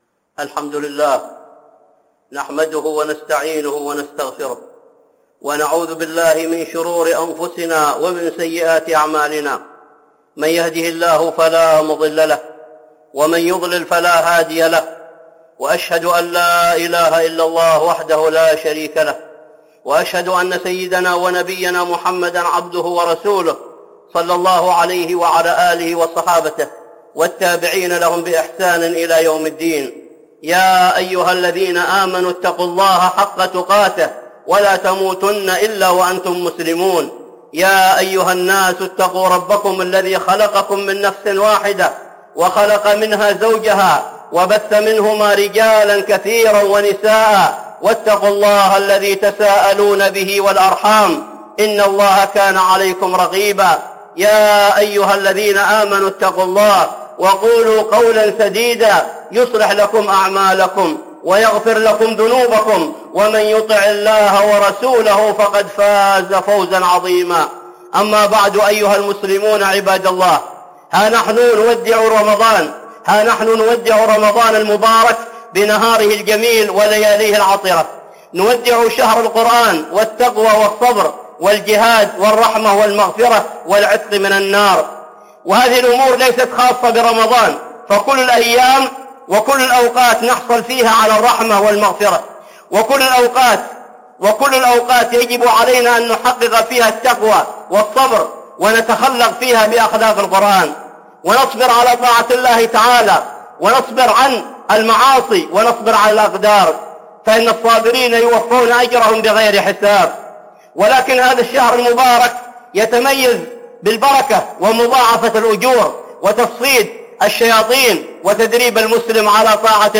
(خطبة جمعة) آخر جمعة في رمضان